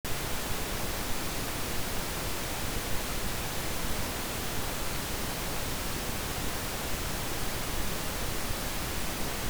Rumore rosa:
mono /
rumore_rosa_-16dB-mono.wav